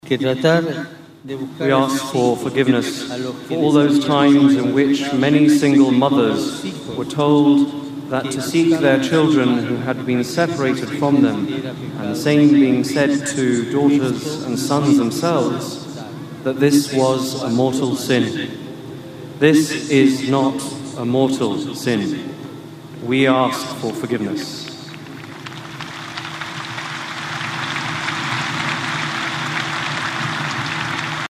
Pope Francis asked for forgiveness from the survivors of abuse as he addressed hundreds of thousands of people in Phoenix Park.